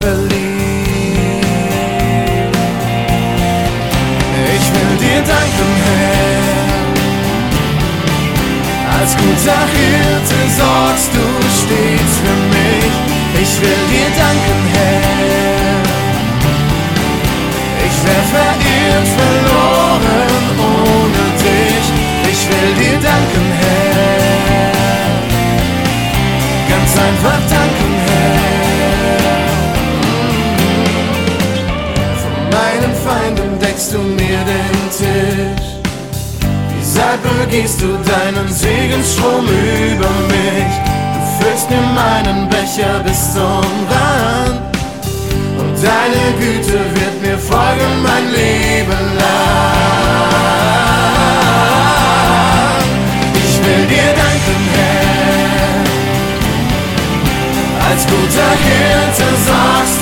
Von Rock über Reggae bis zu Pop ist alles dabei.